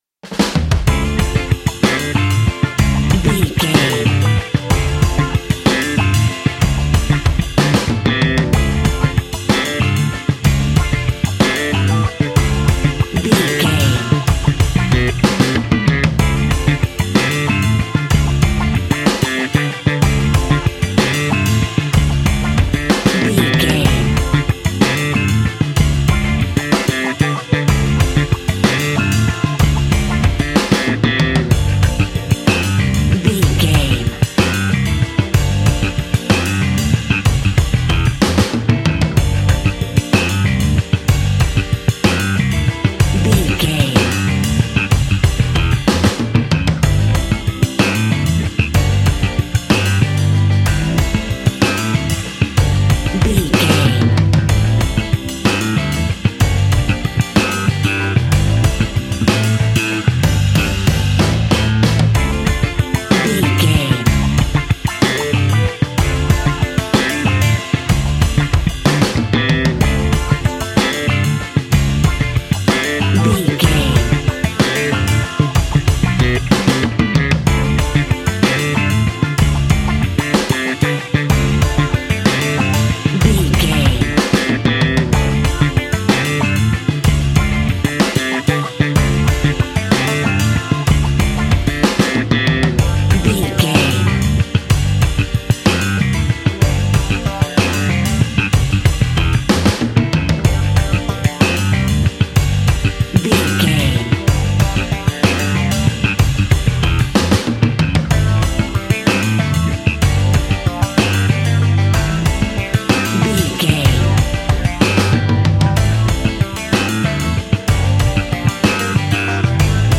Aeolian/Minor
funky
uplifting
bass guitar
electric guitar
organ
saxophone
groovy